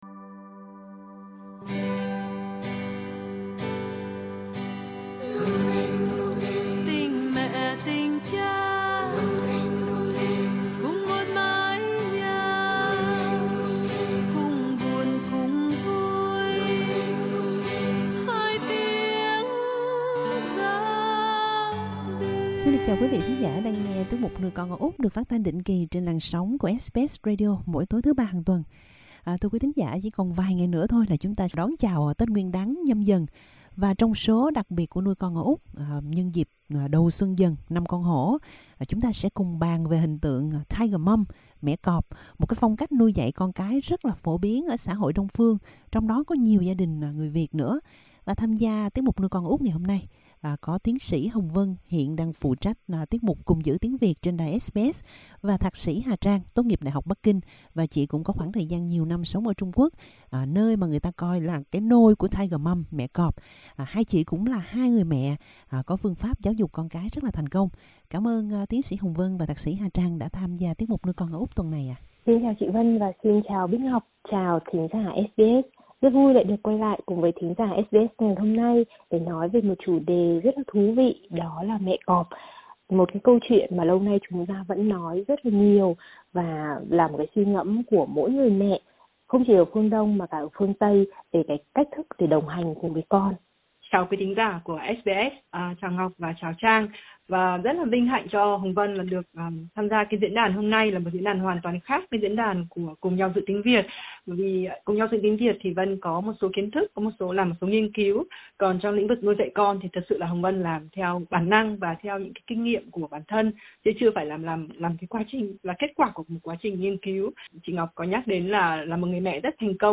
Hai khách mời, một bà mẹ tuổi Dần, một bà mẹ định hướng nhưng không kiểm soát, đồng hành nhưng không độc đoán, cùng tranh luận mẹ cọp hay mẹ gà sẽ tốt hơn?